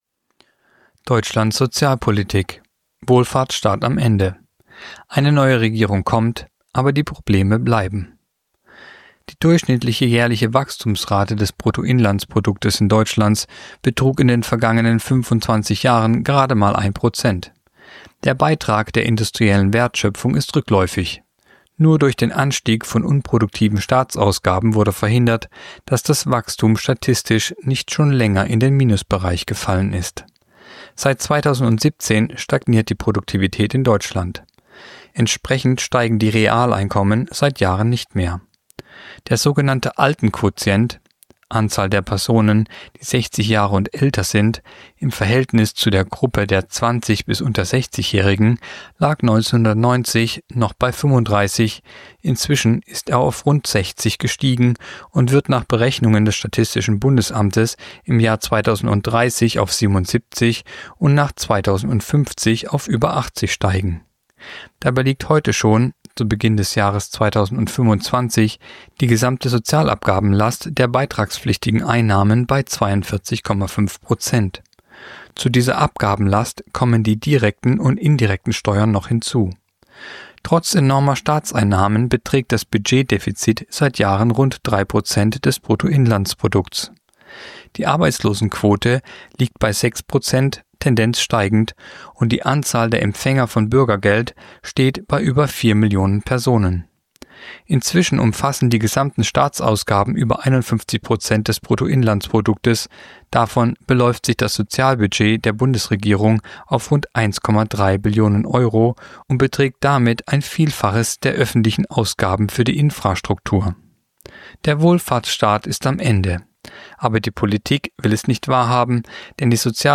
Kolumne der Woche (Radio)Wohlfahrtsstaat am Ende